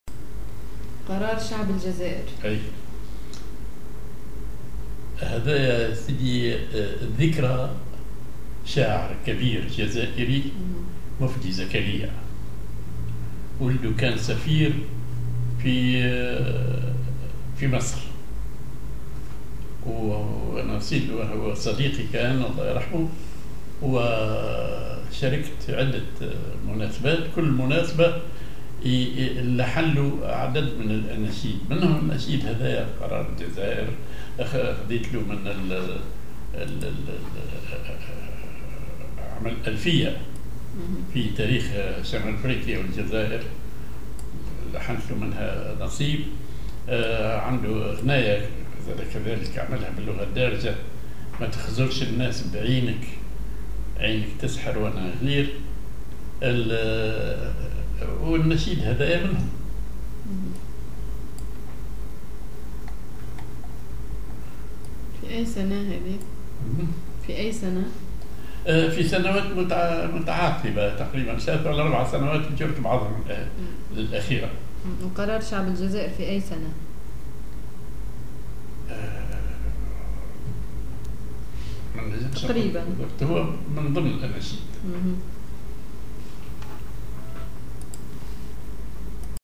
ar سوزناك
ar مصمودي كبير